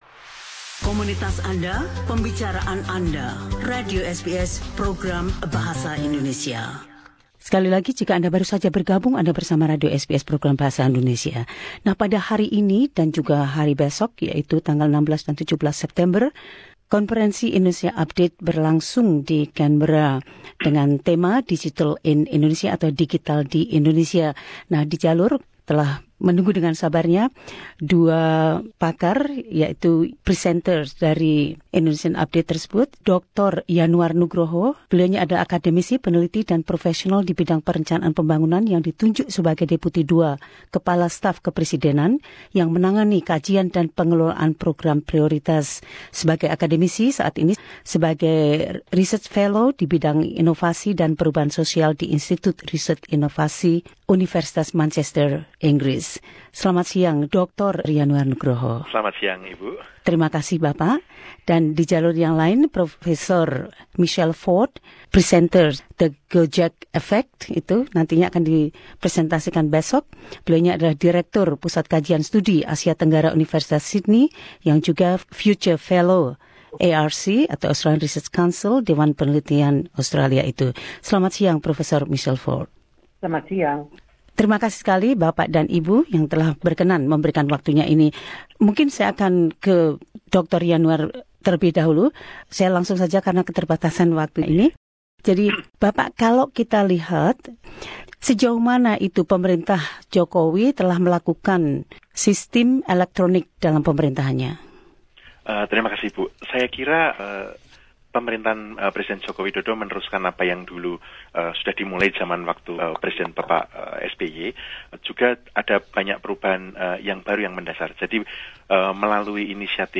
Dua pembicara dalam Konperensi Indonesia Update 2016 di ANU, Dr Yanuar Nugroho, Wakil Kepala Staf di Kantor Eksekutif Presiden Indonesia untuk analisis dan pengawasan strategis isu sosial, kultural dan ekologi.